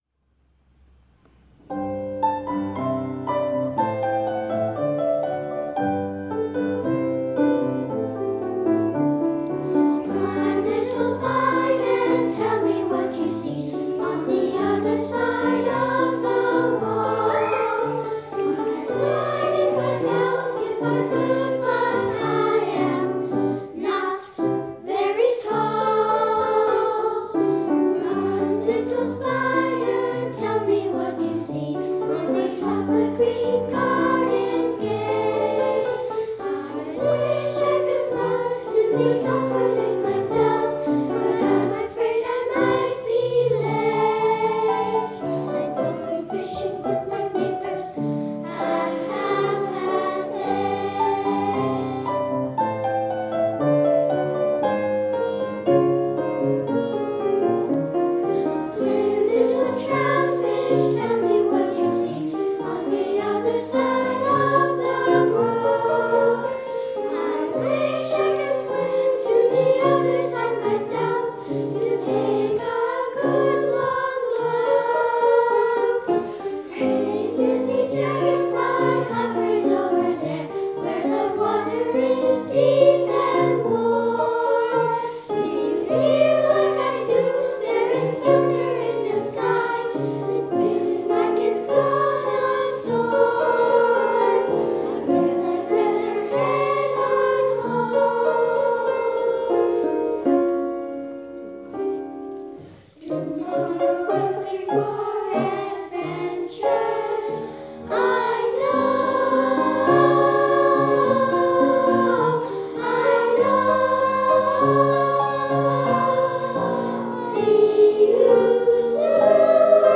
Recording Location: James Bay United Church, Victoria BC
Status: Raw, unedited
The 20-member children's chorus
128kbps Stereo